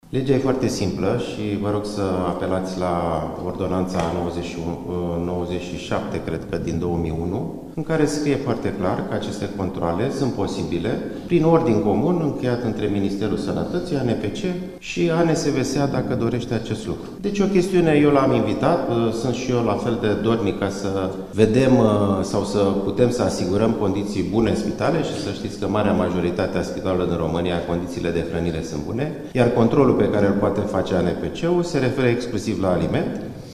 Ministrul Sănătății, Alexandru Rafila: „Aceste controale sunt posibile prin ordin comun încheiat între Ministerul Sănătății și ANPC și ANSVSA, dacă dorește”